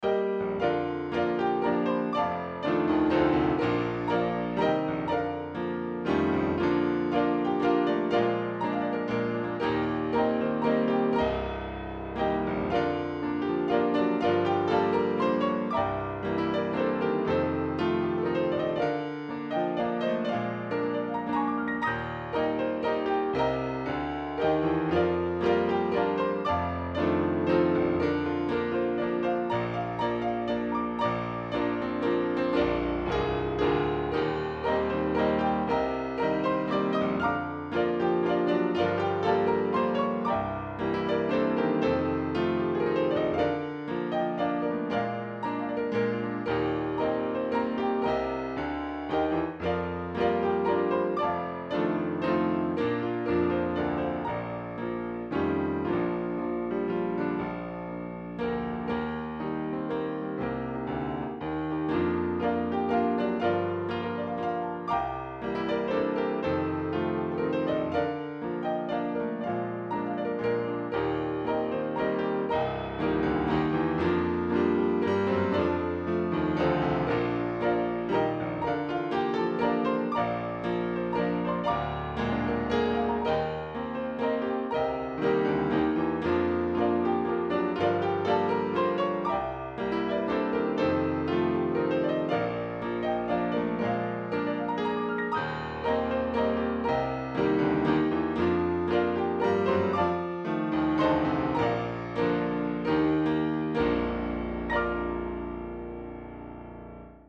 Key: C
Congregational Hymn